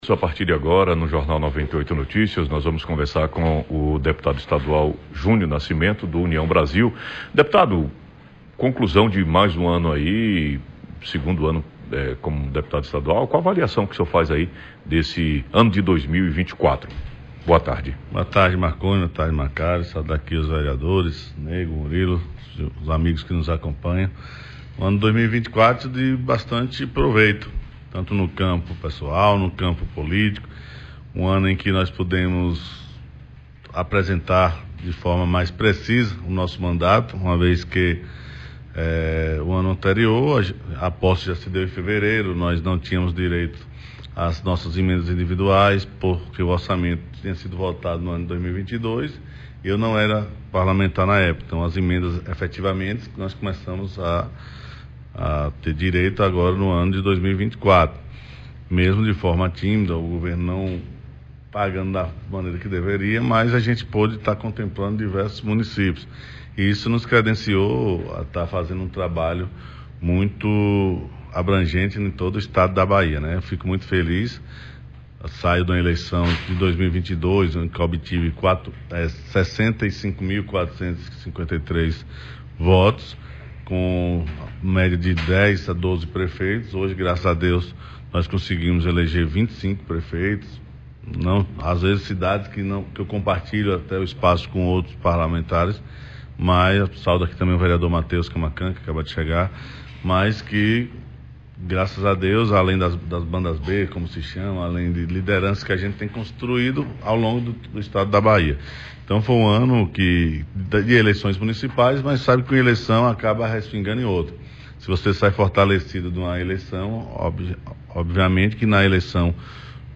Entrevista ao vivo com o deputado Estadual Júnior Nascimento